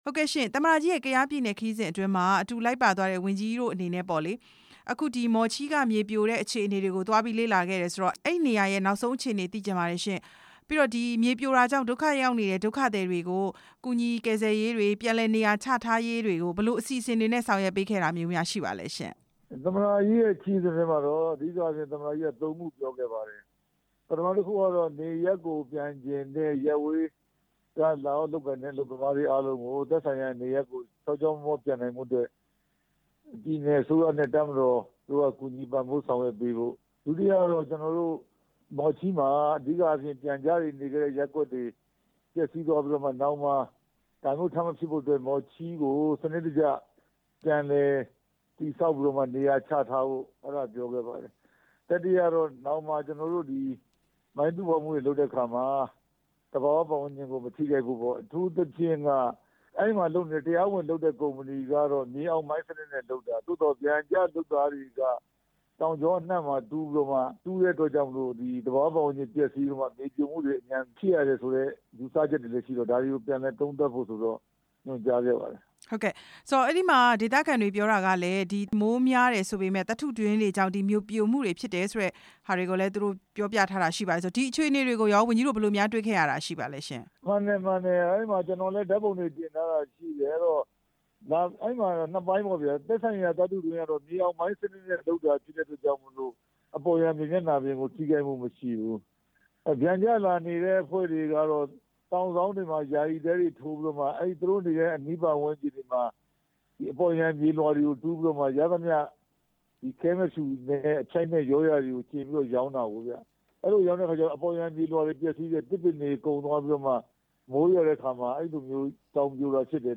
မော်ချီးဒေသမြေပြိုမှု ဝန်ကြီး ဦးရဲထွဋ်နဲ့ မေးမြန်းချက်